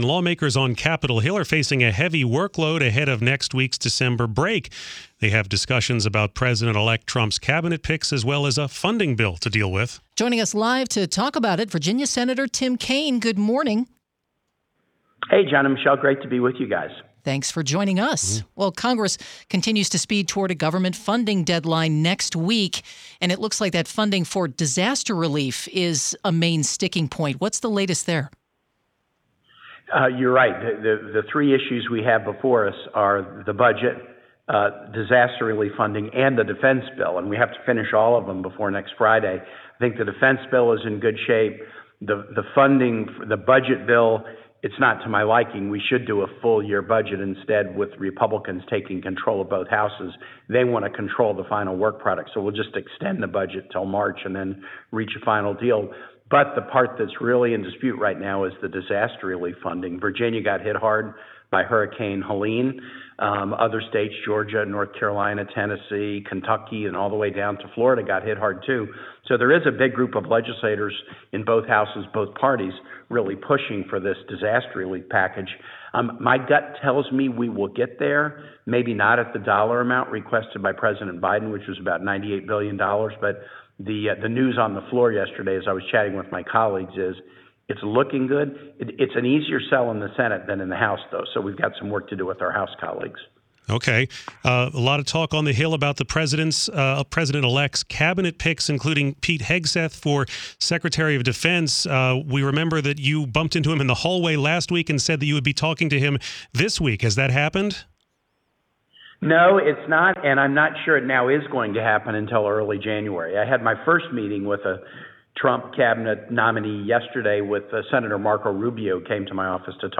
Listen to the full interview below or read the transcript, which has been lightly edited for clarity.